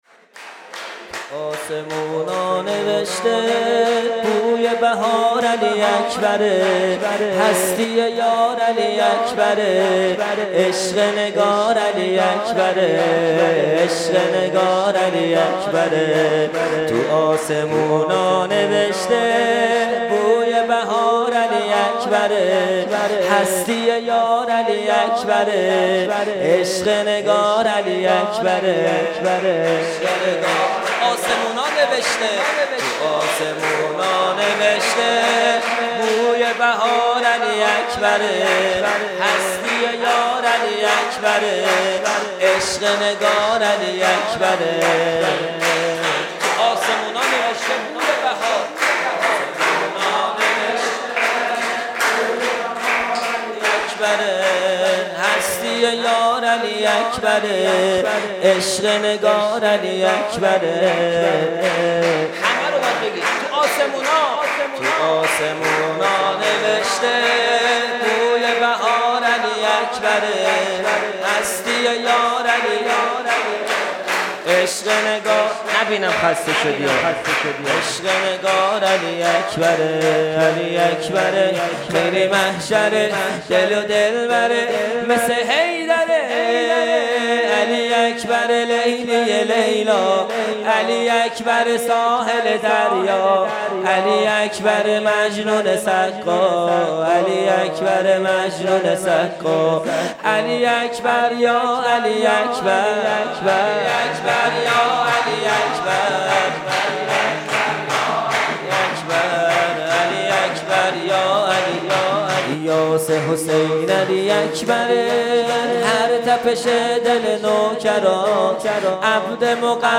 سرود | تو آسمونا نوشته بوی بهار علی اکبره | پنجشنبه۲۶ اسفند۱۴۰۰
جلسه‌ هفتگی | جشن نیمه شعبان | پنج شنبه ۲۶ اسفند ۱۴۰۰